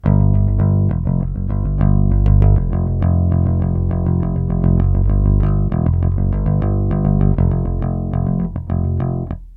SONS ET LOOPS GRATUITS DE BASSES DANCE MUSIC 100bpm
Basse dance 2